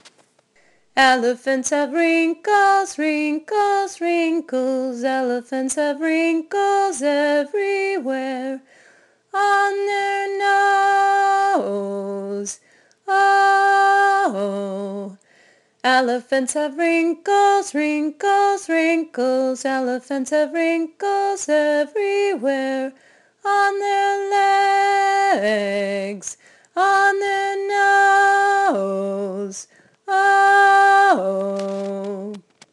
I like to ask the kids for suggestions of where elephants have wrinkles (tonight we did legs, ears, tails, and bellies), and sing the song faster each time.  Click on the triangle for the tune: